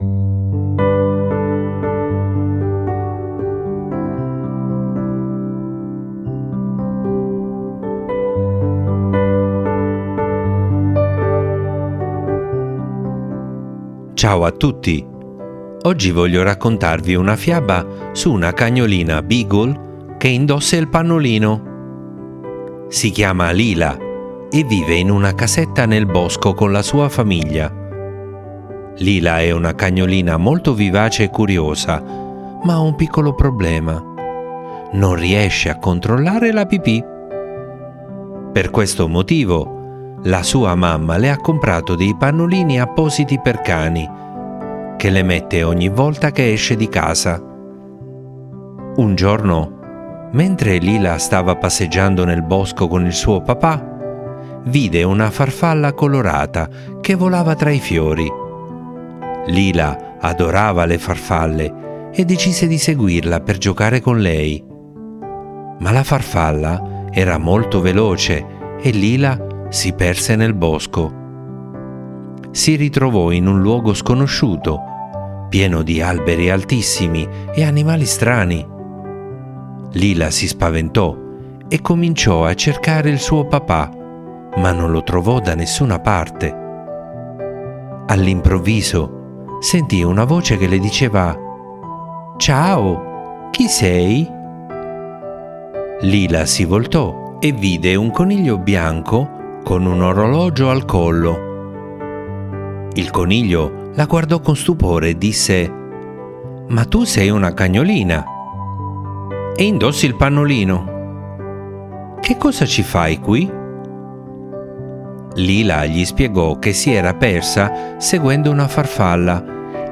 mamma legge la fiaba